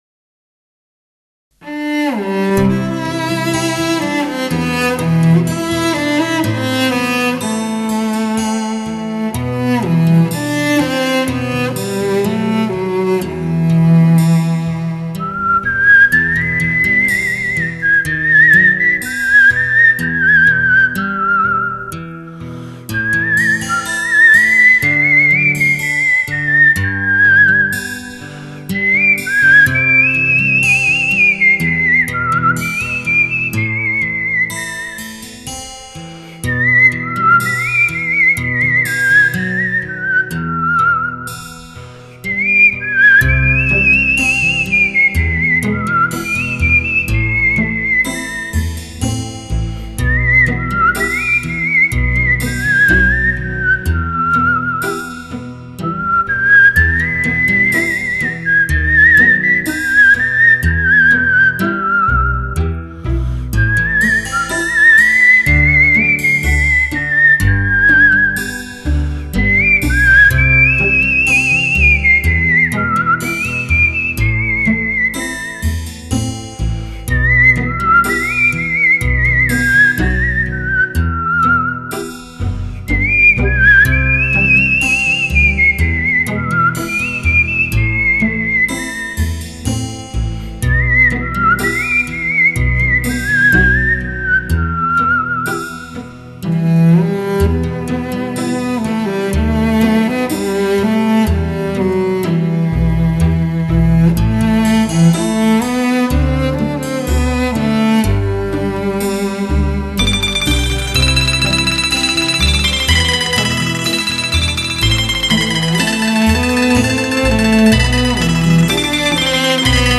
口哨
大提琴
人声